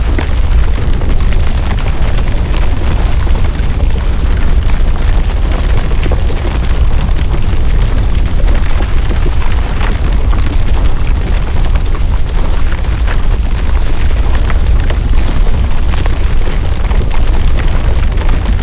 Sfx_creature_iceworm_move_icebreak_loop_01.ogg